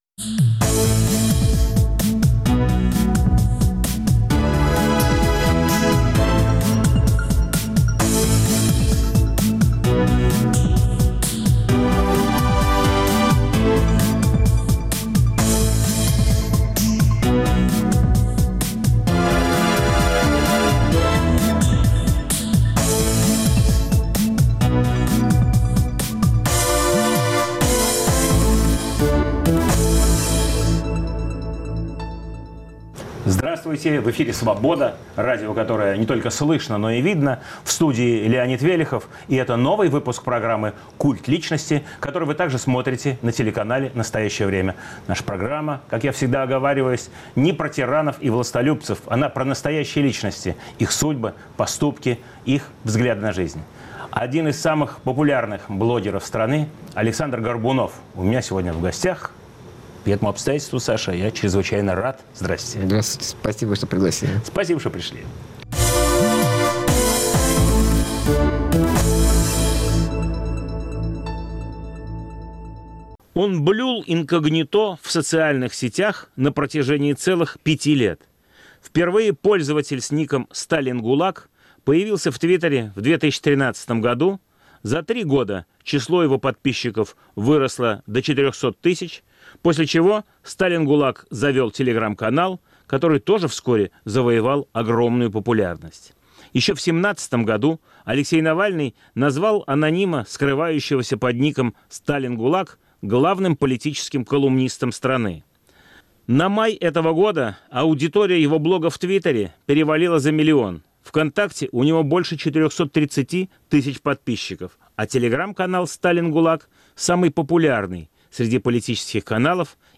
В студии – один из самых популярных блогеров Рунета